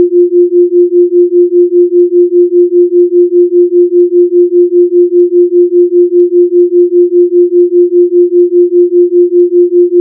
Биения при наложении двух колебаний с частотами 350 Гц и 355 Гц
BeatingSound.ogg